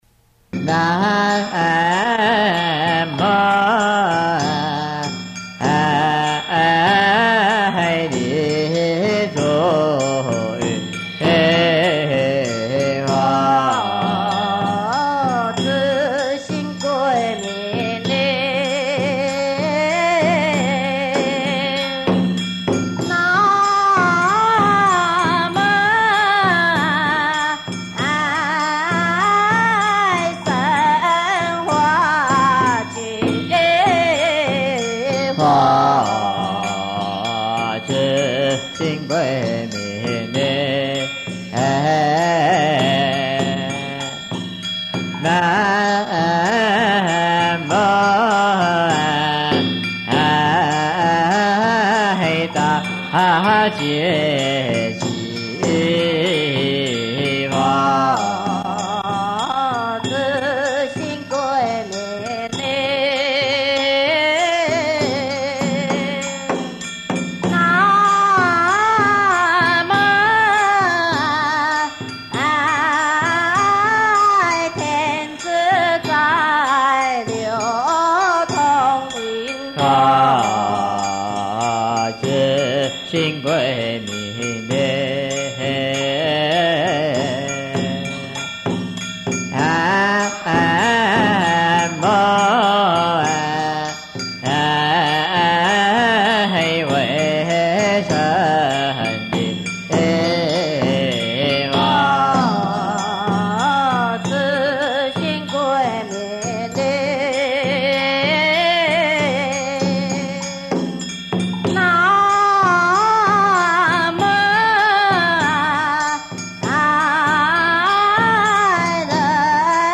經典唱誦/法會佛事 > 三千佛洪名寶懺